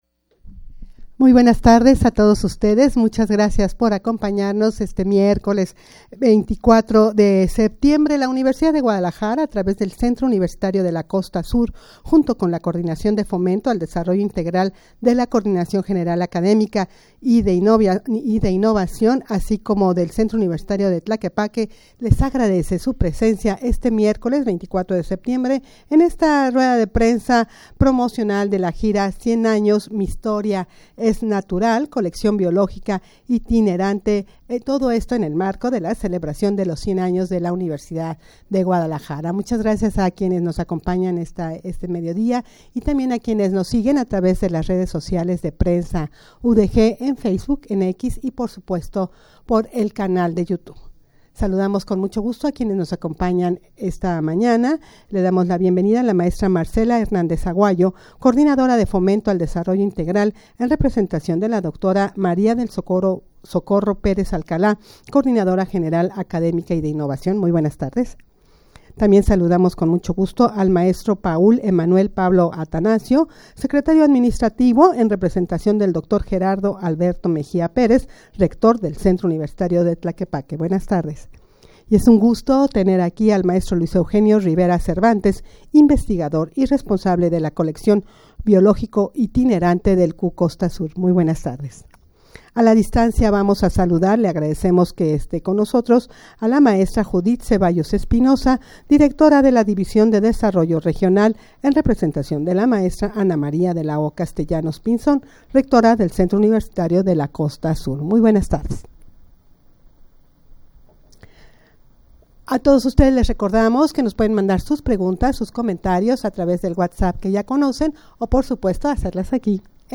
Audio de la Rueda de Prensa
rueda-de-prensa-promocional-de-la-gira-100-anos-mi-historia-es-natural-coleccion-biologica-itinerante.mp3